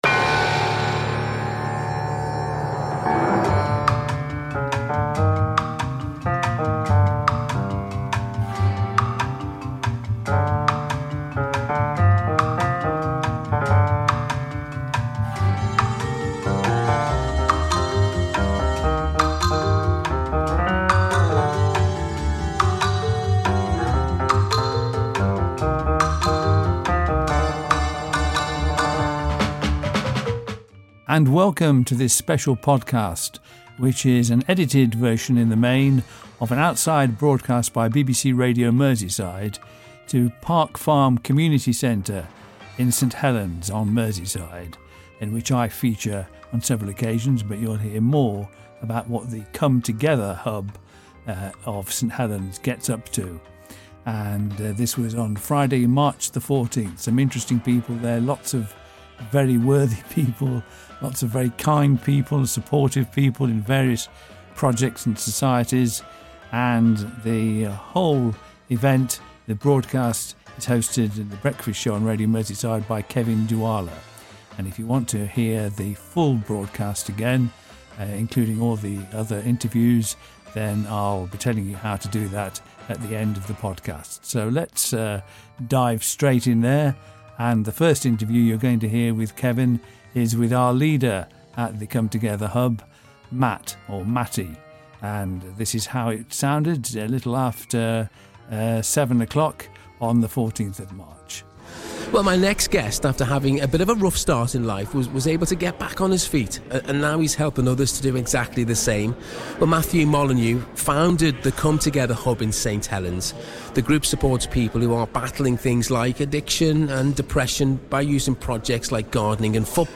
This podcast contains 'edited highlights' of the segments featuring contributions from the Come Together Hub in St Helens, when BBC Radio Merseyside broadcast its breakfast show 'live' from the Park Farm Community Centre. I feature both as an interviewee and question-master!